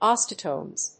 osteotomes.mp3